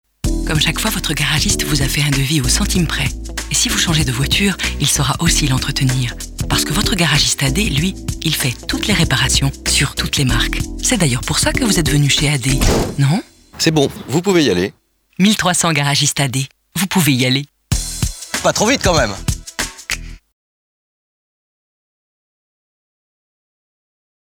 Voix off
Pub collective des bijoux